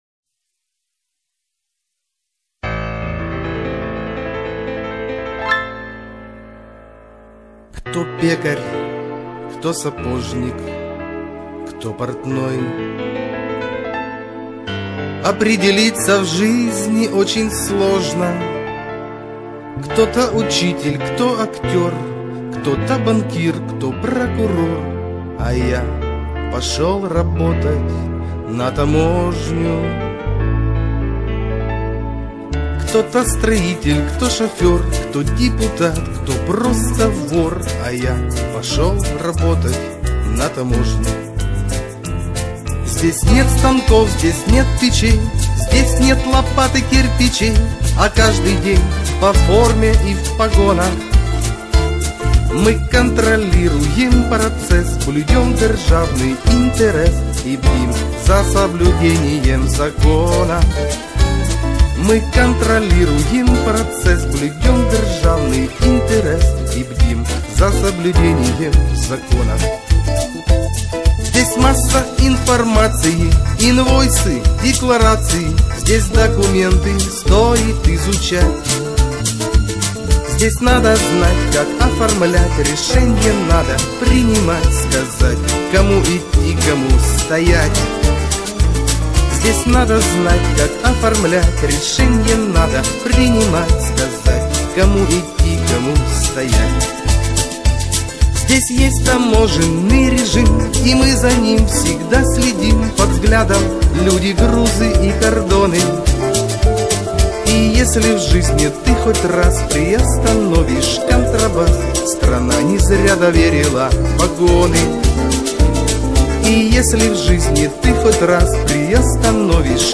народная